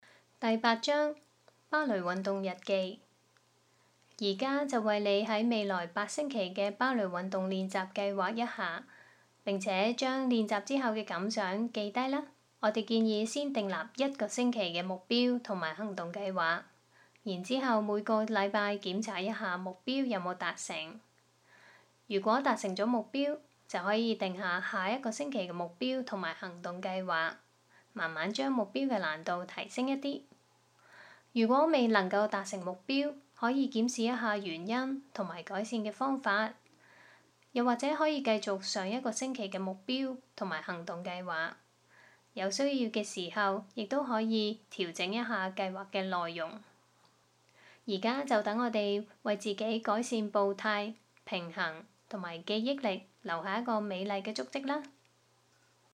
第八章 芭蕾運動日記 第八章 芭蕾運動日記 聲音導航 Download audio 現在就為你於未來八星期的芭蕾運動練習計劃一下，並將你練習後的感想記錄下來。